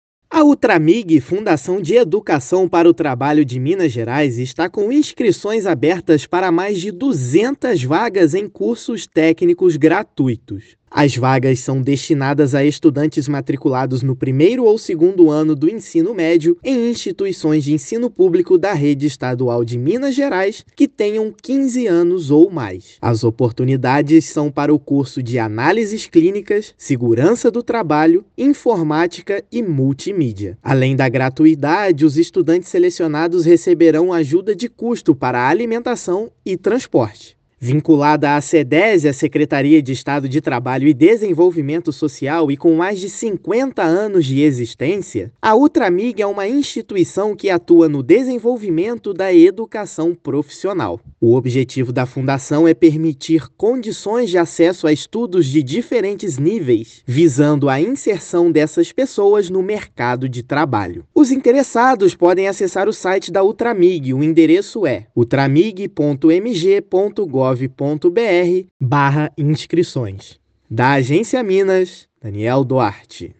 [RÁDIO] Fundação de Educação para o Trabalho de Minas tem mais de 200 vagas para cursos técnicos gratuitos
Estudantes interessados nas oportunidades oferecidas pela Utramig devem estar matriculados no 1º ou 2º ano do ensino médio. Ouça a matéria de rádio: